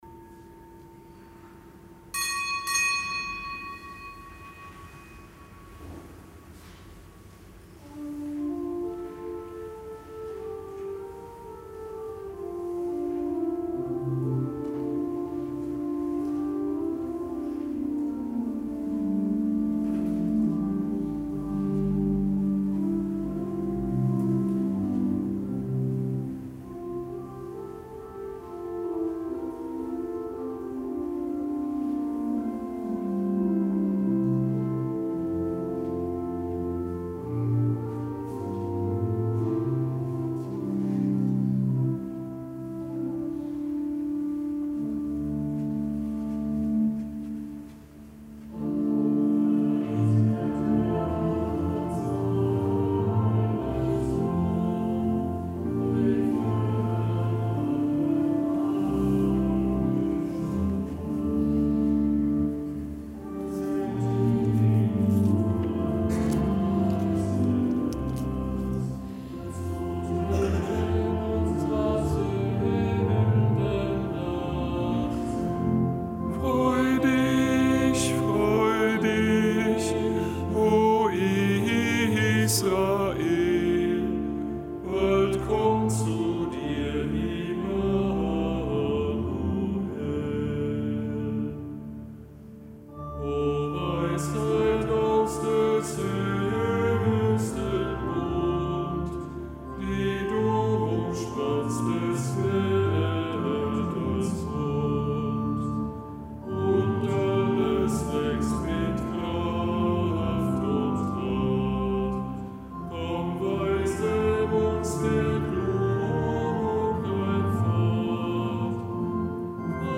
Kapitelsmesse aus dem Kölner Dom am Mittwoch der dritten Adventswoche; Zelebrant: Weihbischof Ansgar Puff.